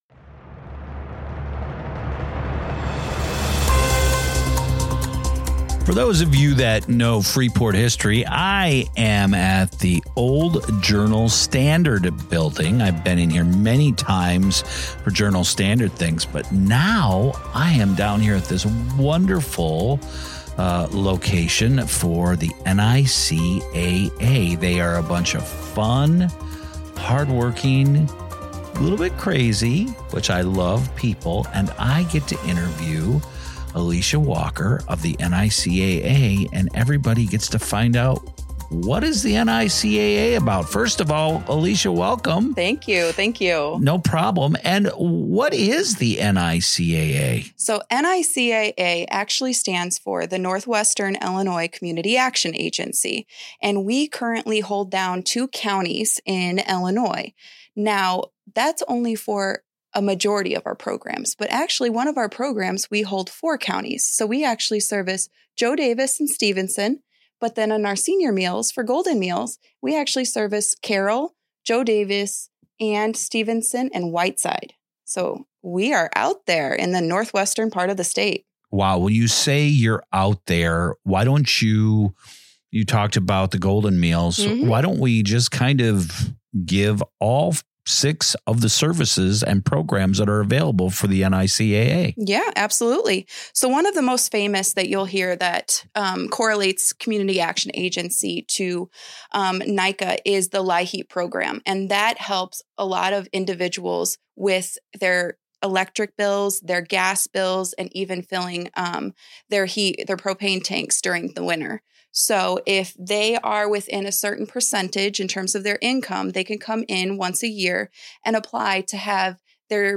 Freepod Interview